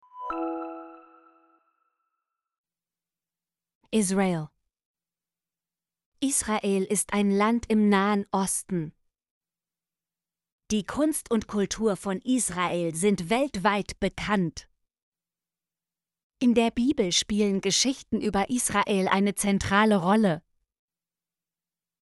israel - Example Sentences & Pronunciation, German Frequency List